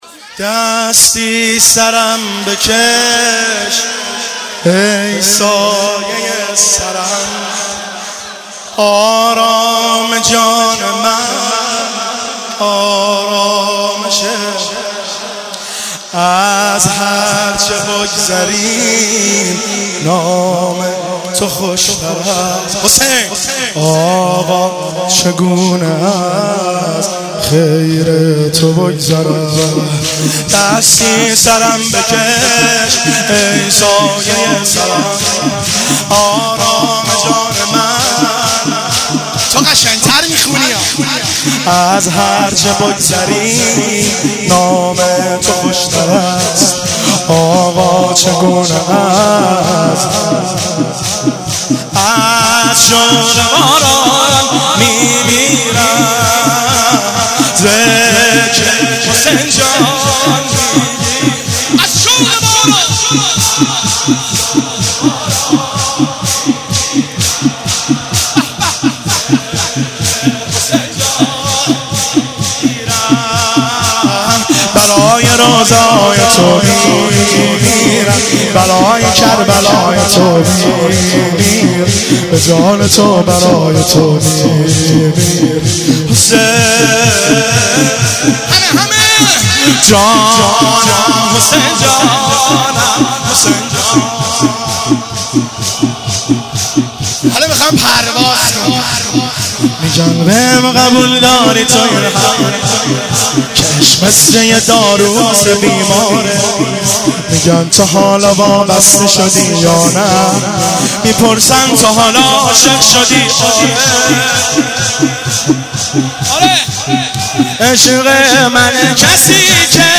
مجموعه نوحه های جلسه هفتگی
( شور )